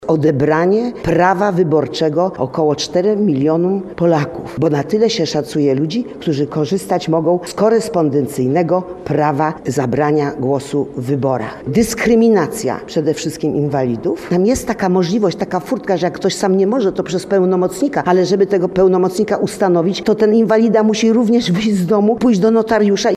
– Takie rozwiązanie powoduje duże utrudnienia – ocenia radna Mazowsza Dorota Stalińska.